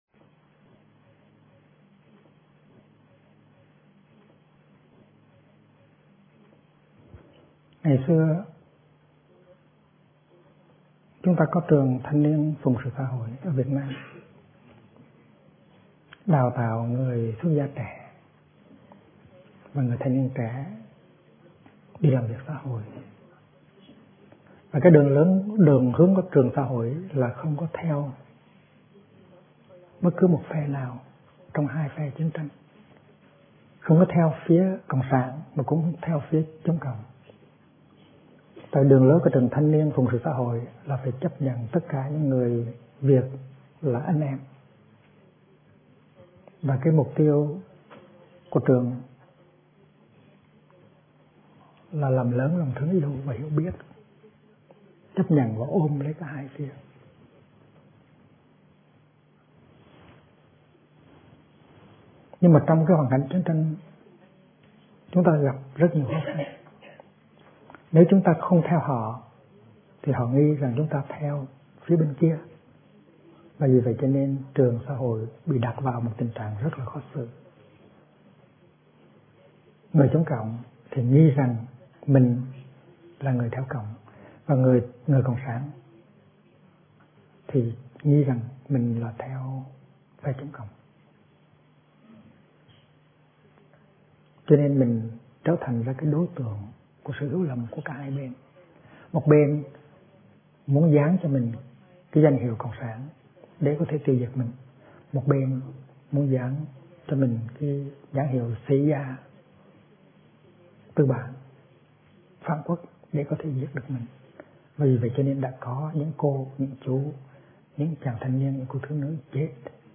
Kinh Giảng Chặt Bỏ Ảo Ảnh - Thích Nhất Hạnh